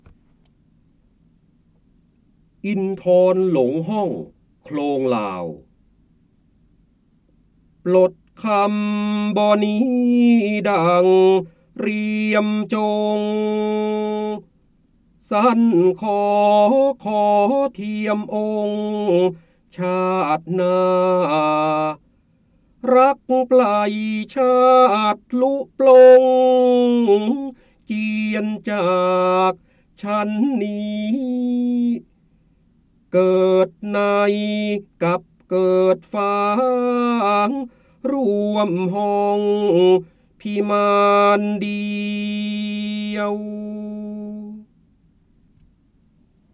เสียงบรรยายจากหนังสือ จินดามณี (พระโหราธิบดี) อินทรหลงห้องโคลงลาว